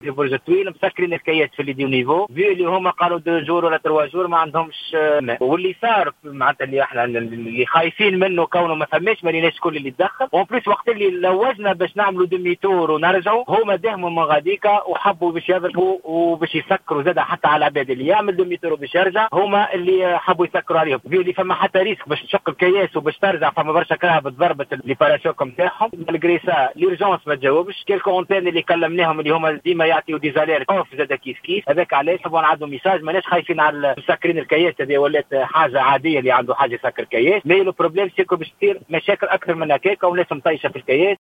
عمد أشخاص ظهر اليوم الاثنين إلى قطع الطريق الرابطة بين تونس وبنزرت "احتجاجا على انقطاع الماء". وتوجّه مواطن على مستوى برج الطويل عبر "الجوهرة أف أم" بنداء إلى السلطات التونسية للتدخل بسبب تعطل حركة السير وبقاء المواطنين على طول الطريق بعد منع مرور سيارتهم التي لحق بعضها أضرار.
شاهد عيان